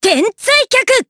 Cecilia-Vox_Skill4_jp_b.wav